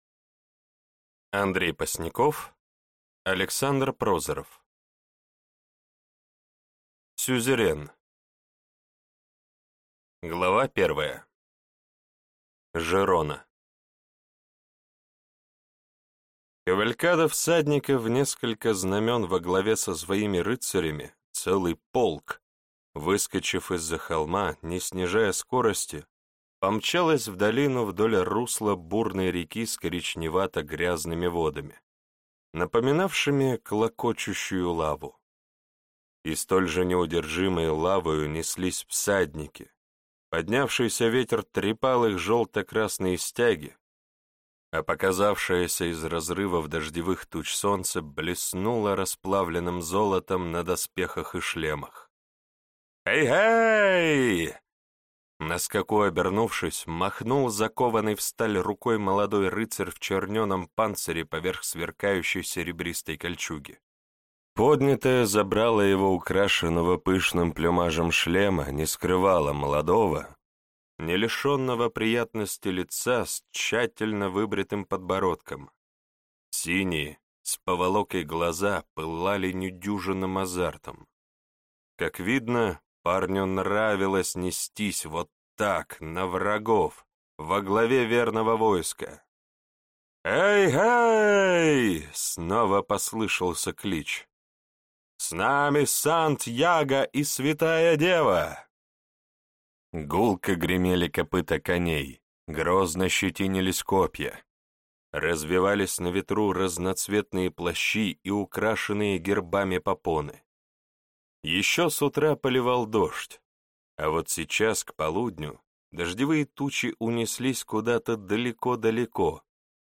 Аудиокнига Ватага. Император. Часть 3. Сюзерн | Библиотека аудиокниг